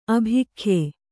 ♪ abhikhye